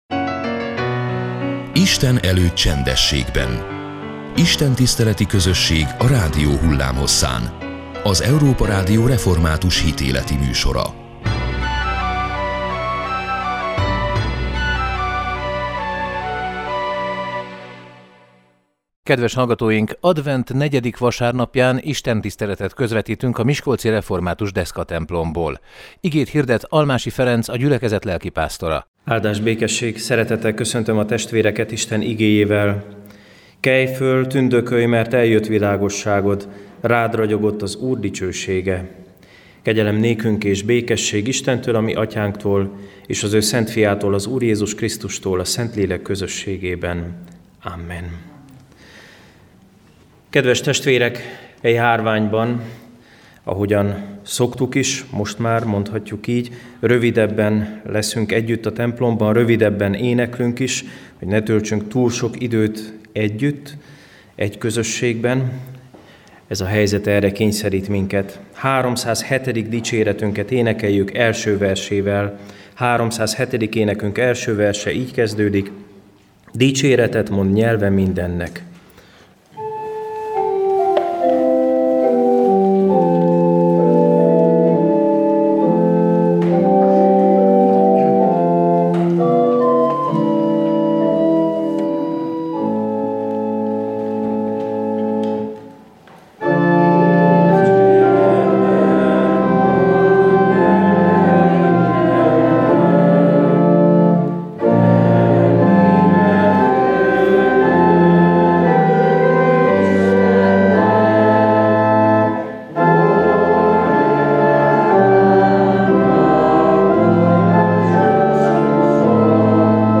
Advent negyedik vasárnapján istentiszteletet közvetítettünk a miskolci református Deszkatemplomból.